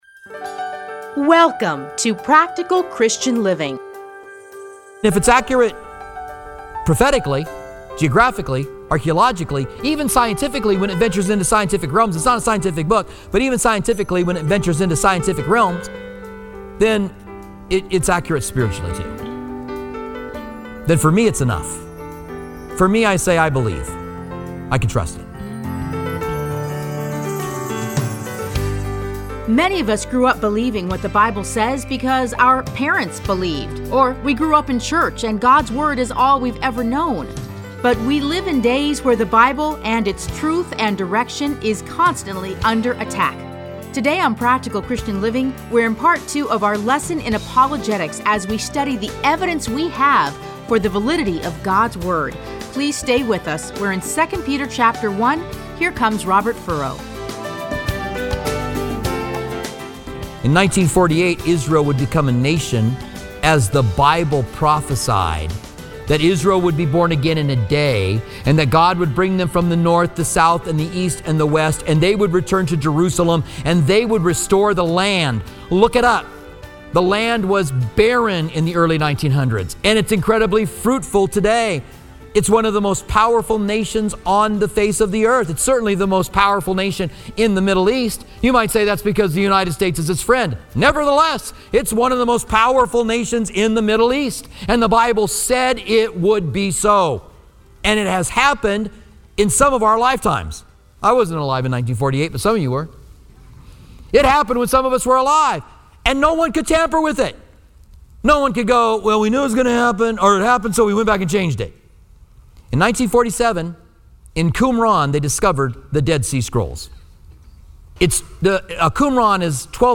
Listen to a teaching from 2 Peter 1:16-21.